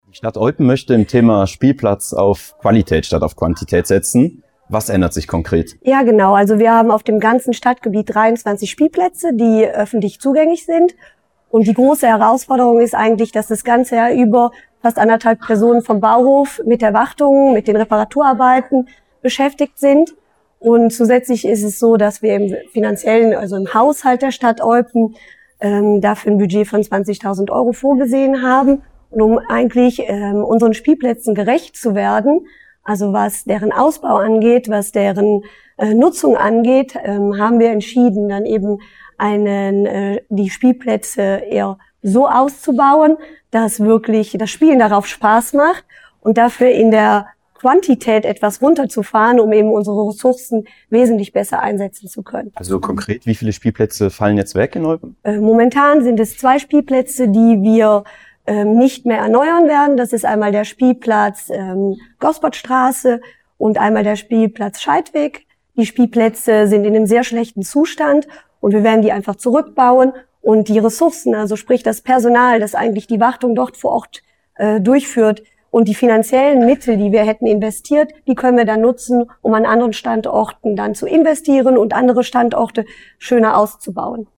sprach über das Thema mit der zuständigen Schöffin Joëlle Birnbaum-Köttgen.